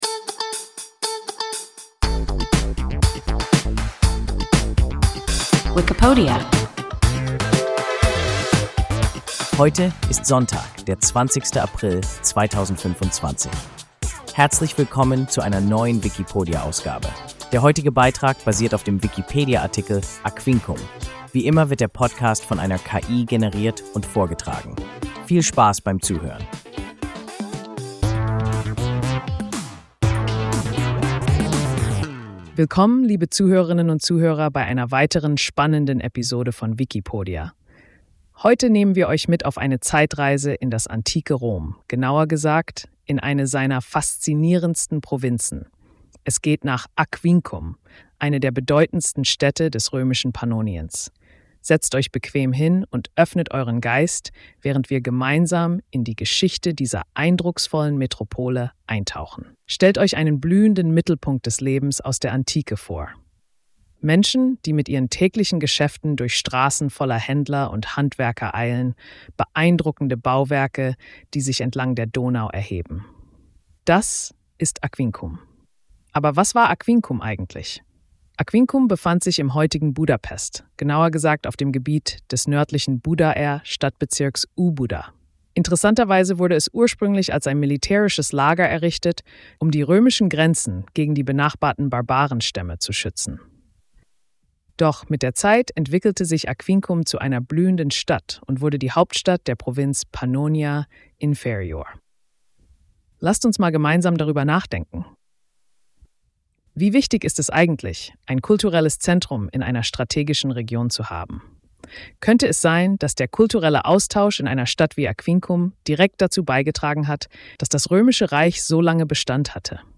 Aquincum – WIKIPODIA – ein KI Podcast